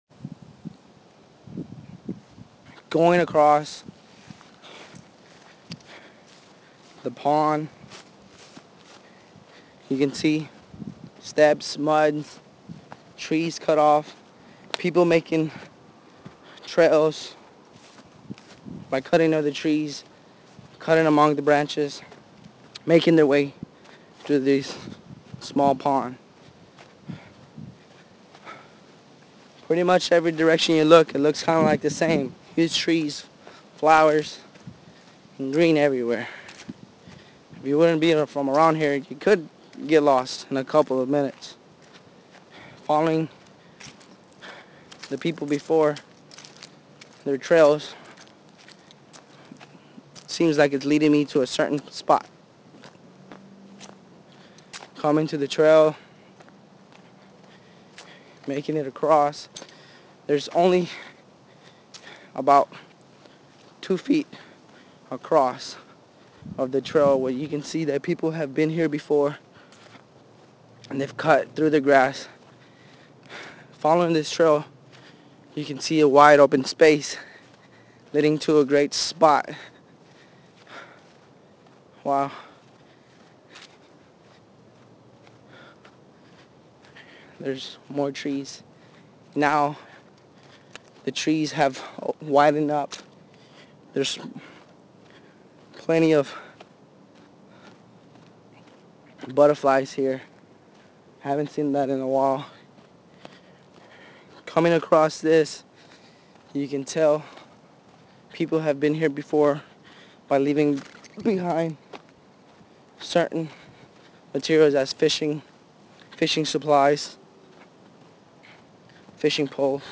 audio walking tours